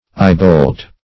eyebolt - definition of eyebolt - synonyms, pronunciation, spelling from Free Dictionary Search Result for " eyebolt" : The Collaborative International Dictionary of English v.0.48: Eyebolt \Eye"bolt`\, n. (Mach.) A bolt with a looped head, or an opening in the head.